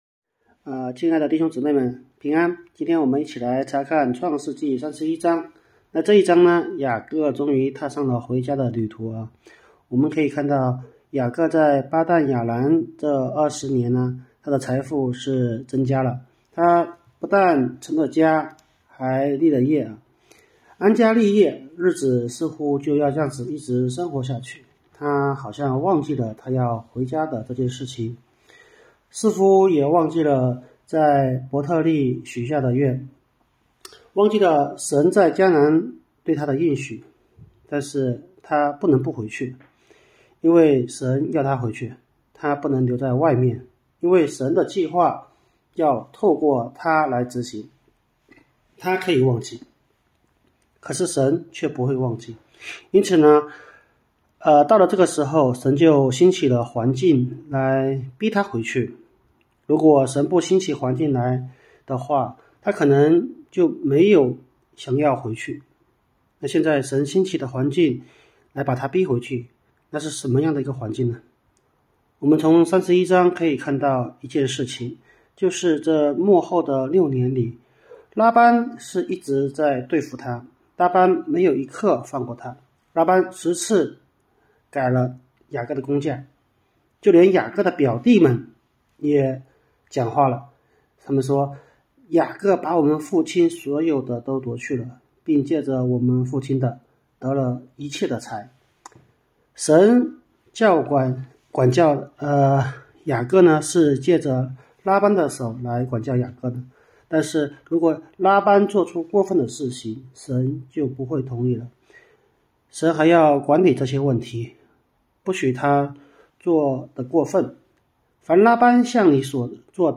问答式查经——《创世记》（31）